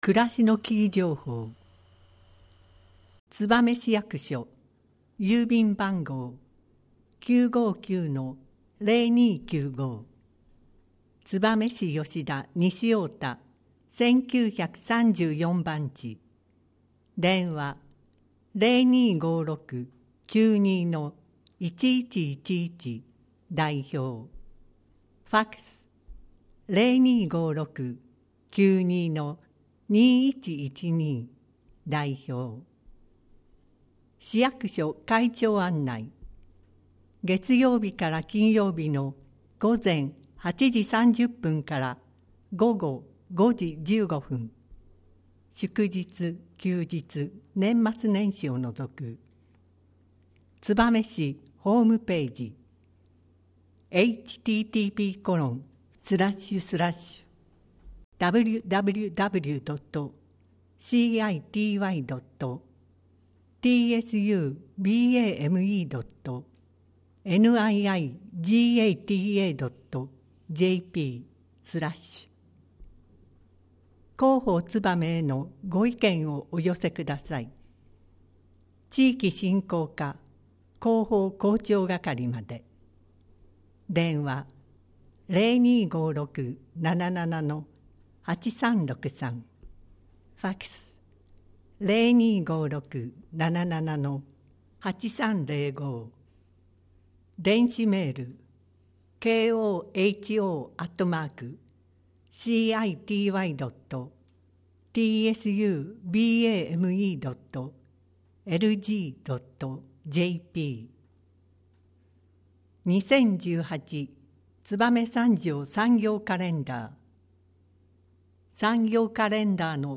声の広報つばめ2018年8月15日号